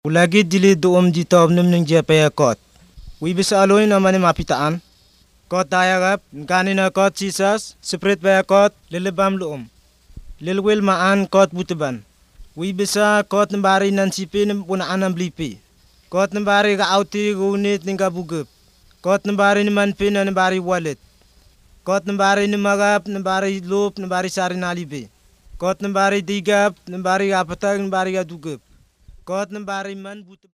Words of Life recordings contain short Bible stories, evangelistic messages and songs. They explain the way of salvation and give basic Christian teaching. Most use a storytelling approach. These are recorded by mother-tongue speakers